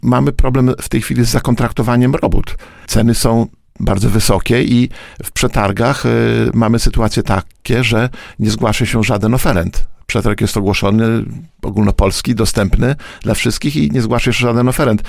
Mamy problem ze znalezieniem wykonawców inwestycji drogowych – mówił w Poranku Siódma9, starosta łomżyński Lech Szabłowski.